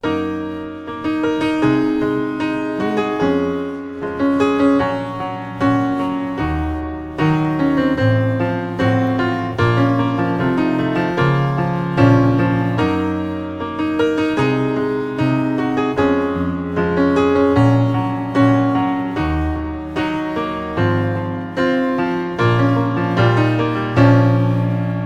• Качество: 320, Stereo
красивые
без слов
пианино